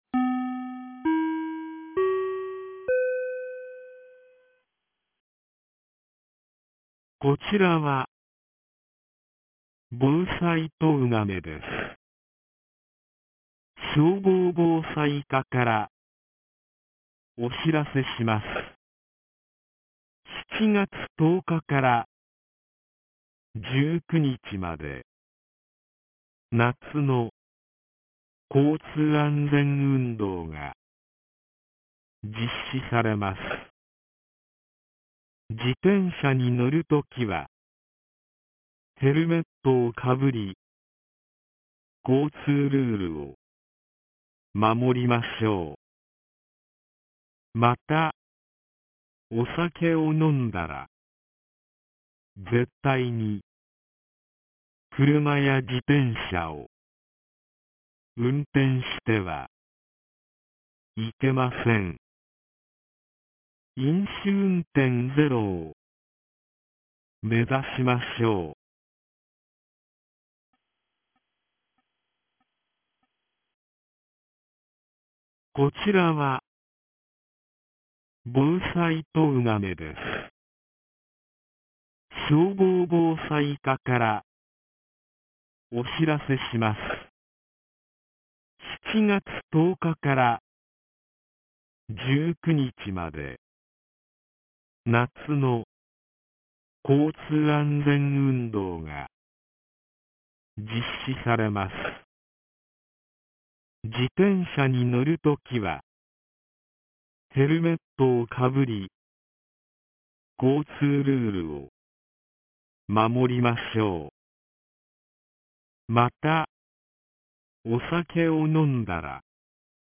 2025年07月15日 16時02分に、東金市より防災行政無線の放送を行いました。